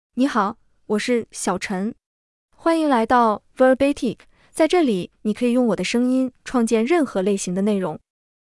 Xiaochen — Female Chinese (Mandarin, Simplified) AI Voice | TTS, Voice Cloning & Video | Verbatik AI
XiaochenFemale Chinese AI voice
Xiaochen is a female AI voice for Chinese (Mandarin, Simplified).
Voice sample
Listen to Xiaochen's female Chinese voice.
Female